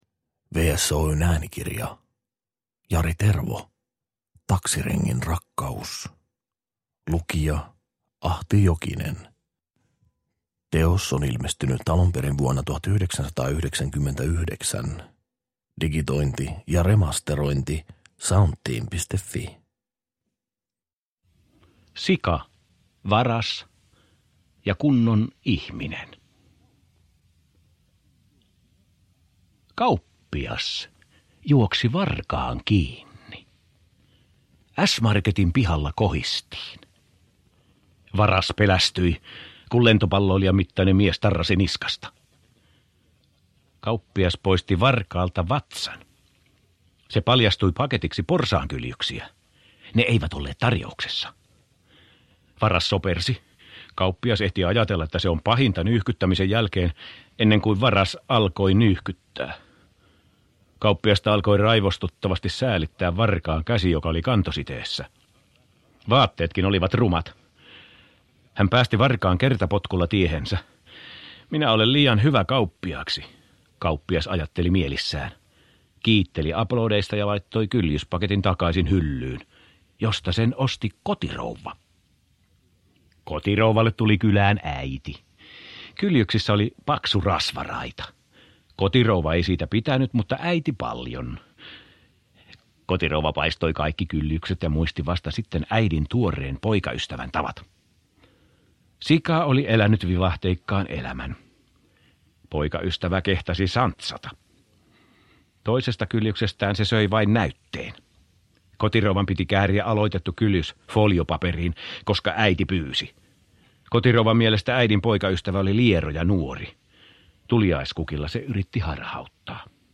Taksirengin rakkaus – Ljudbok